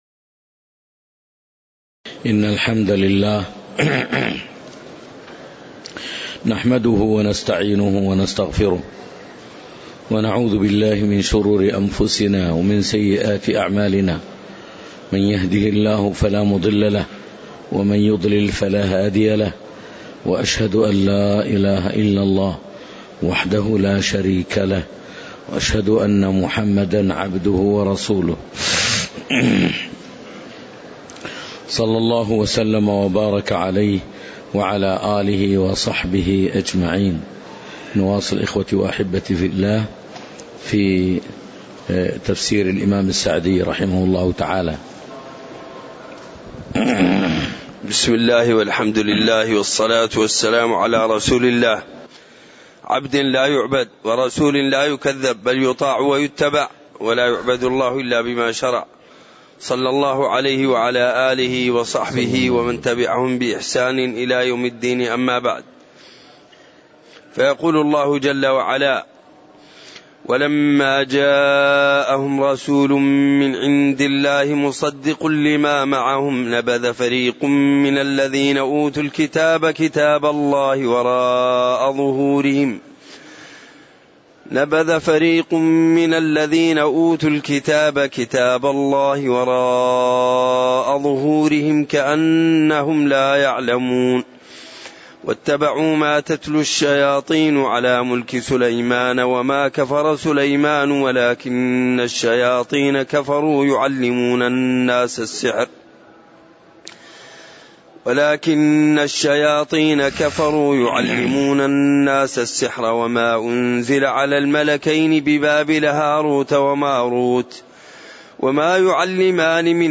تاريخ النشر ٢٥ ربيع الأول ١٤٣٨ هـ المكان: المسجد النبوي الشيخ